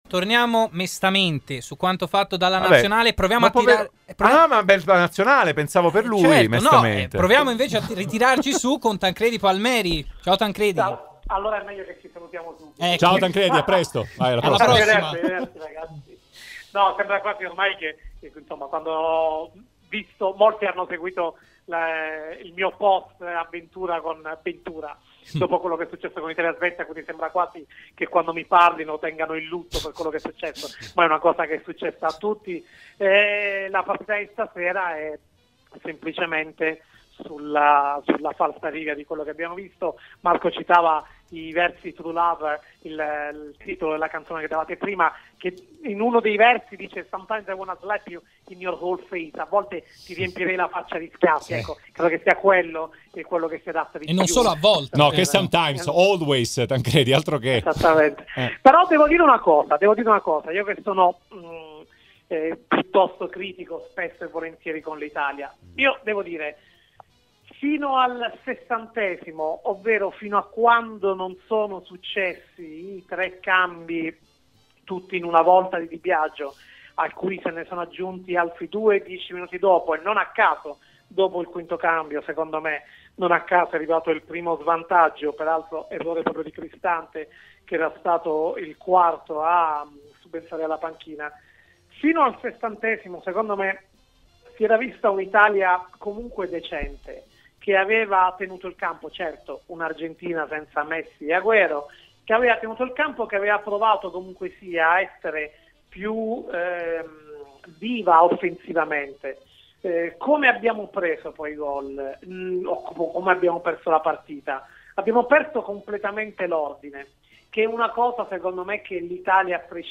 a commento nel post partita di Argentina-Italia.